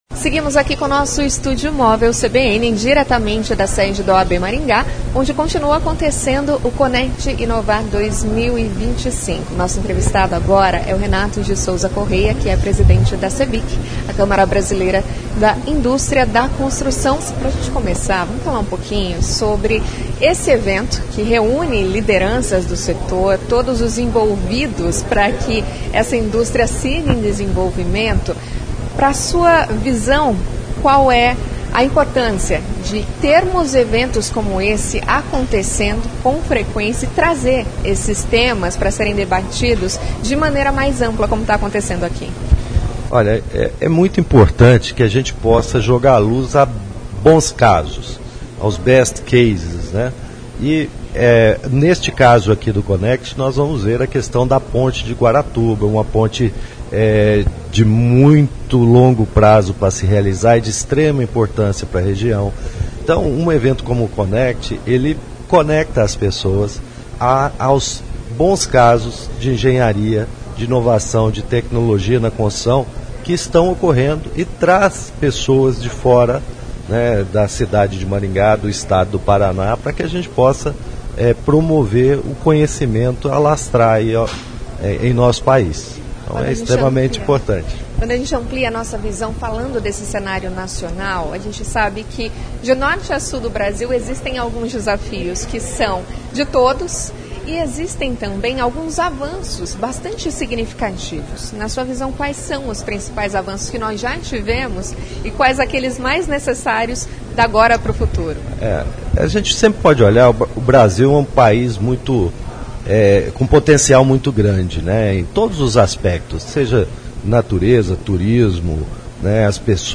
A entrevista foi realizada no Estúdio Móvel da CBN, instalado na sede da OAB Maringá, de onde ocorre a edição do Conecti, que antecede a cerimônia de entrega do Prêmio Sinduscon 2025.